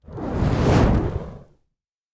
mag_fire_projectile_002.wav